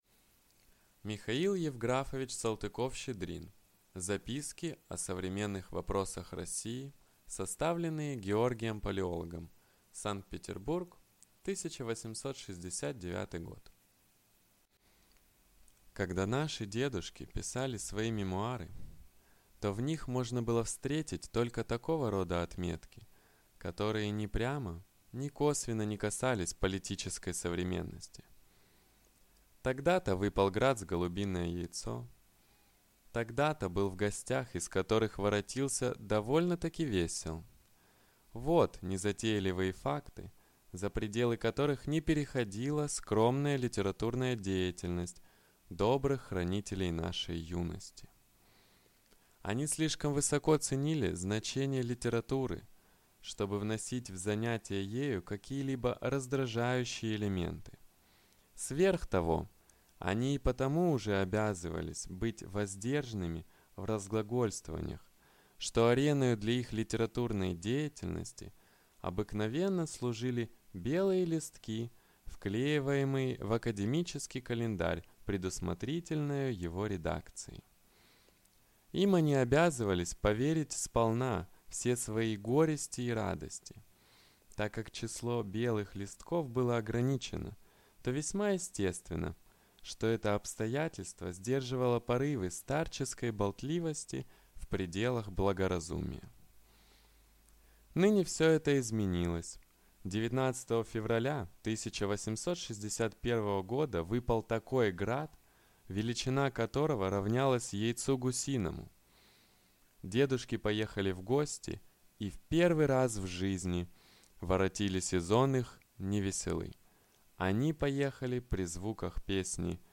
Аудиокнига Записки о современных вопросах России | Библиотека аудиокниг